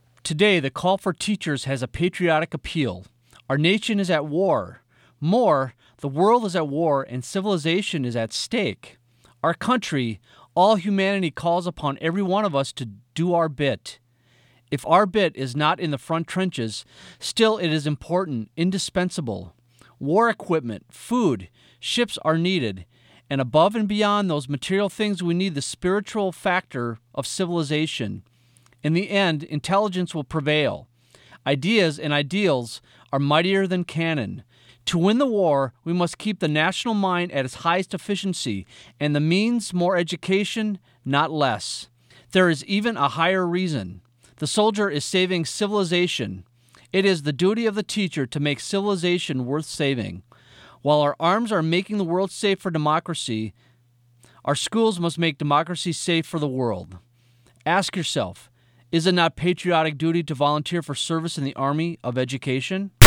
Recorded partial reading of an article entitled "Why Teach?"